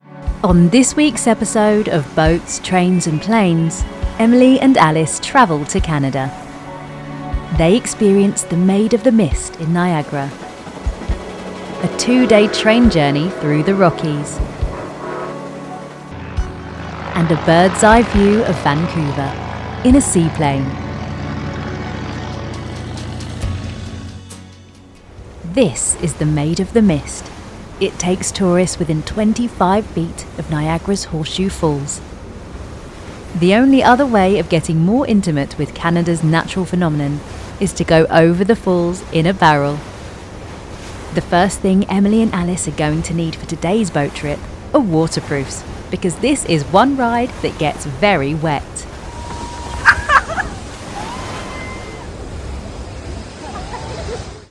Documentary Narration
RP ('Received Pronunciation')